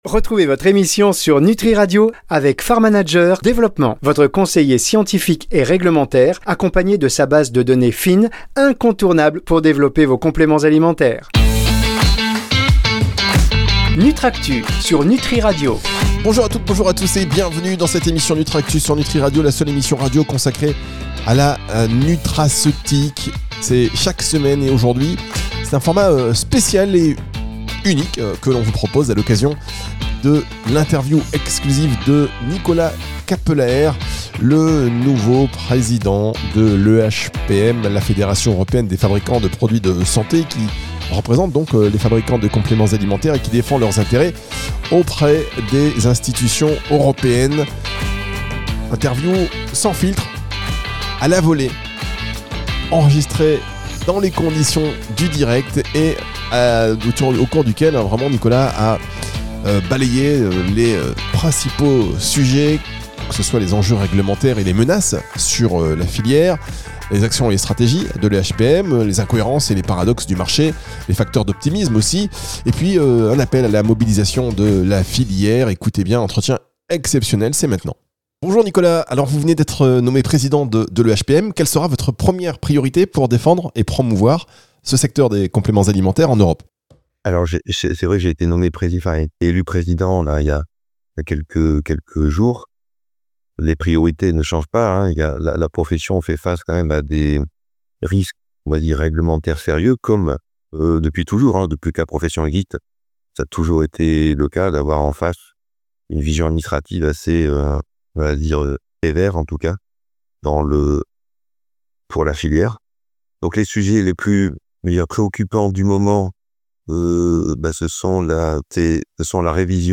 Pourquoi ces ingrédients sont-ils dans le viseur des autorités ? Quels enjeux pour la filière et les consommateurs ? Comment l’EHPM se mobilise-t-elle pour défendre le secteur ? Écoutez notre entretien exclusif.